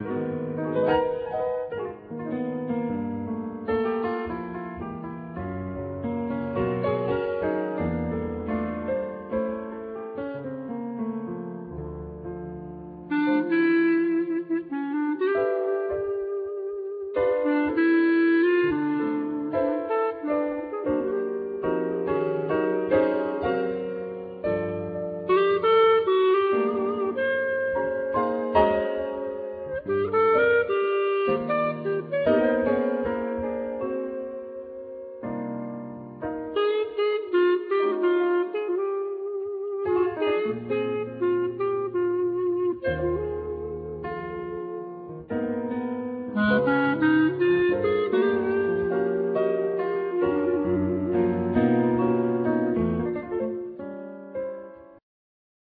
Clarinet
Piano